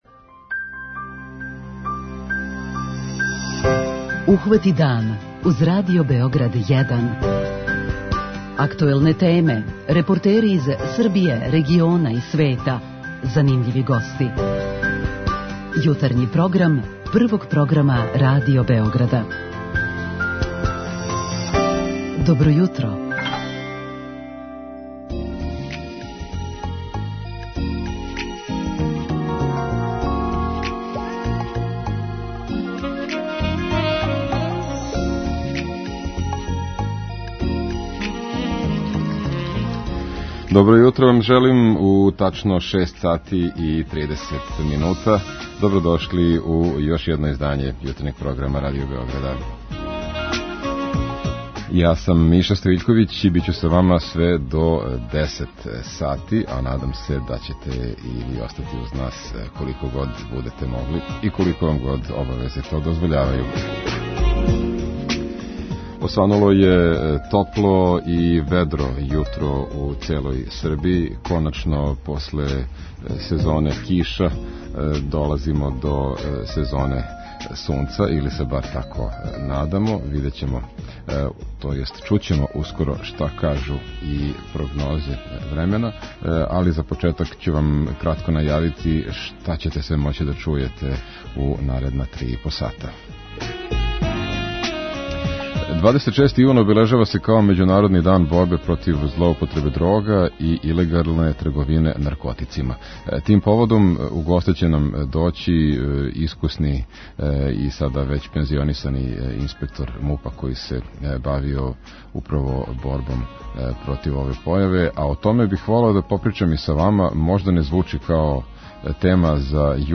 Питаћемо и слушаоце како смањити злоупотребу наркотика међу младима.